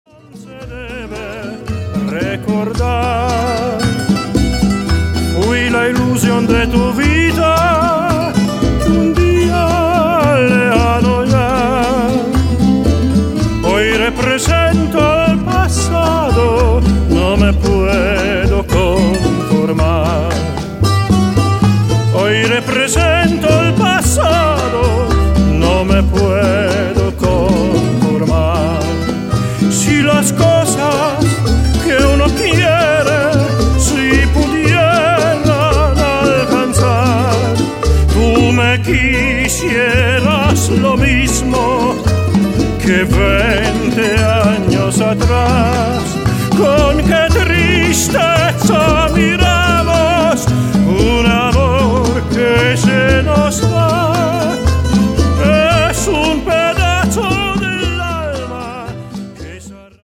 Grandioser Gesang